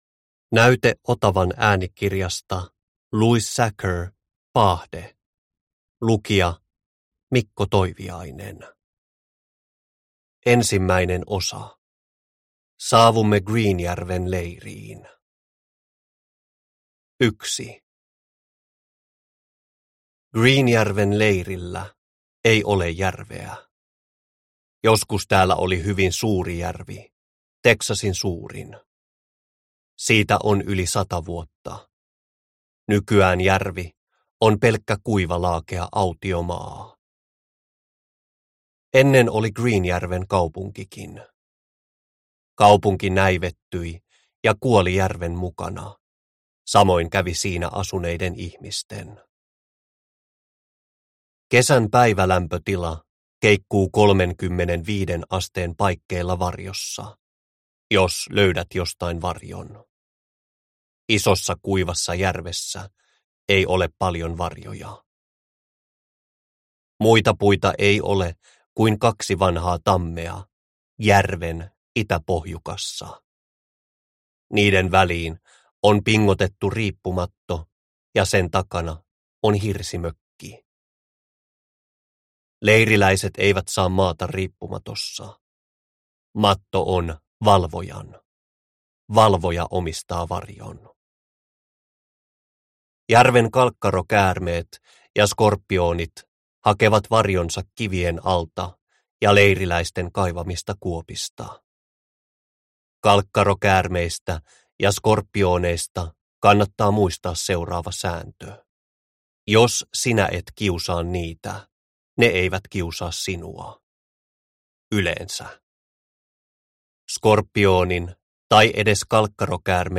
Paahde – Ljudbok